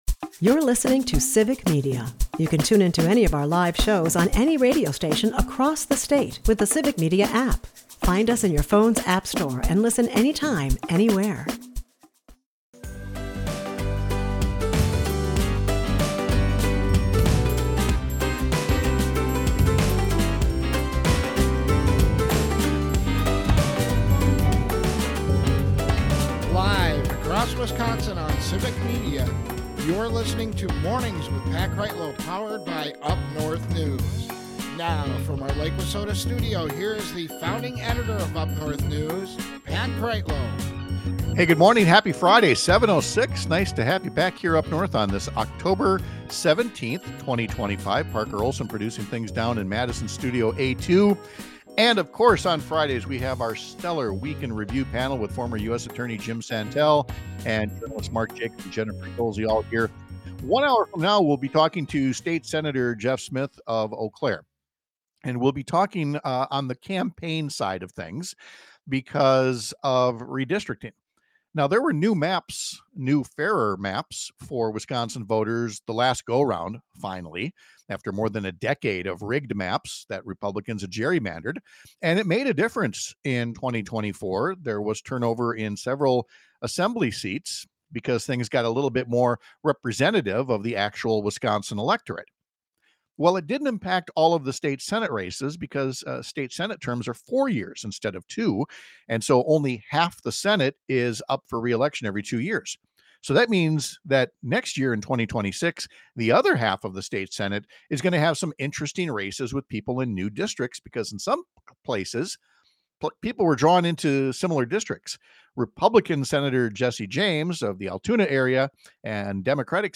We’ll ask our Week In Review panel if there’s any path forward when a wannabe king shuts down the government and basically becomes the government as a result.